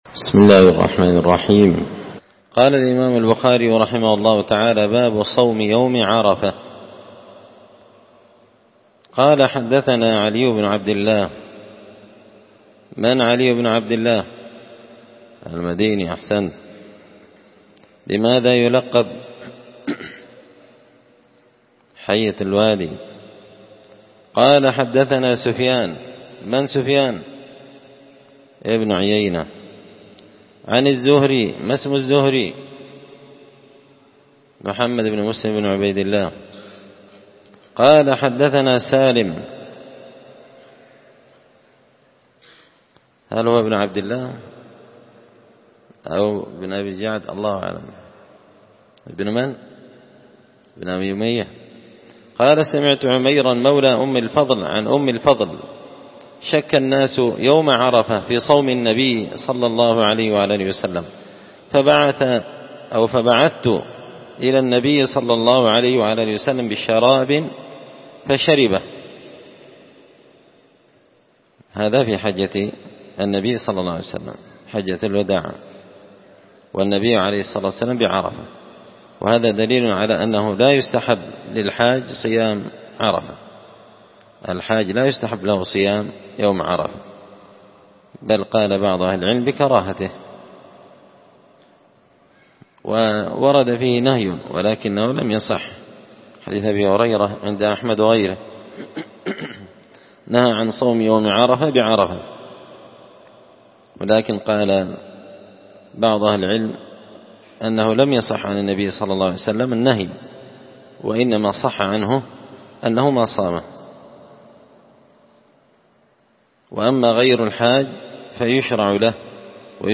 كتاب الحج من شرح صحيح البخاري – الدرس 77